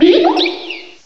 cry_not_munna.aif